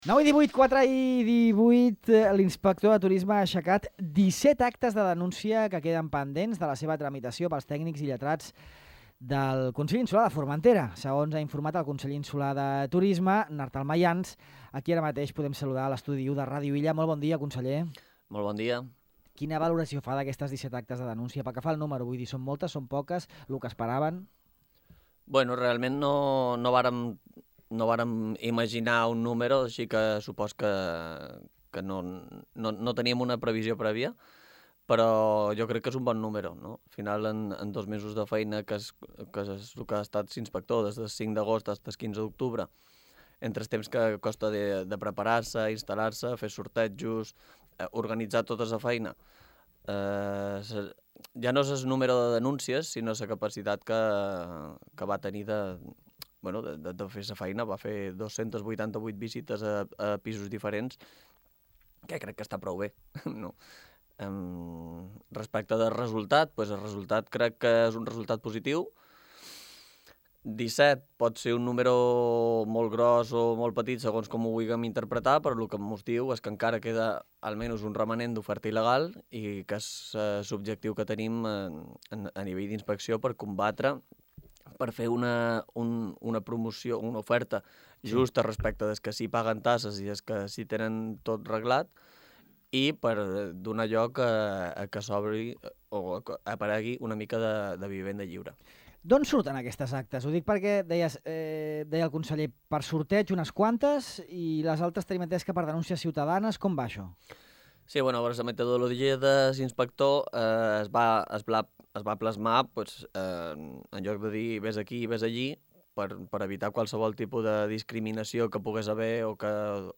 Ara per ara les 17 actes de denúncia que ha aixecat l’inspector turístic a Formentera durant aquesta temporada no es poden tramitar com a sancions perquè aquesta és una “competència autonòmica” i, per tant, haurien de ser aprovades per la -actualment inexistent- Junta de Govern del Consell, segons ha explicat a Ràdio Illa Artal Mayans, conseller insular de Turisme.